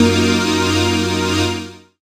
37hc01syn-f#.wav